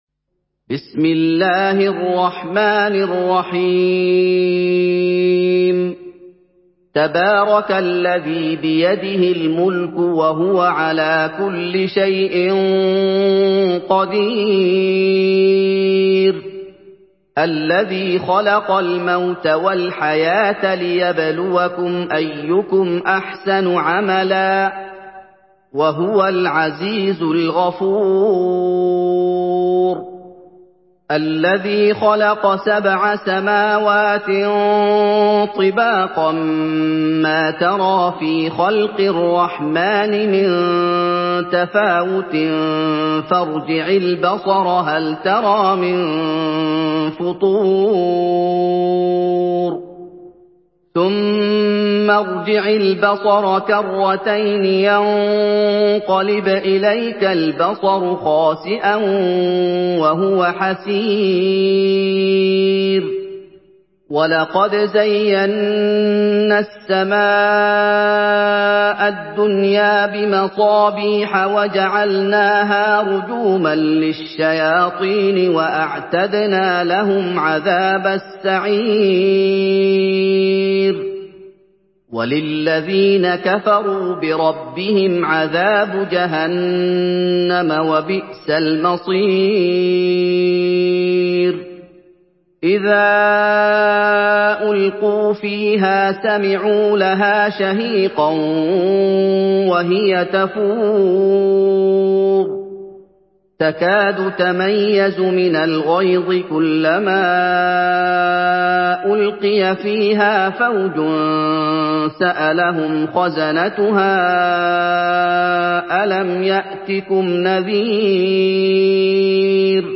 Surah Mülk MP3 by Muhammad Ayoub in Hafs An Asim narration.
Murattal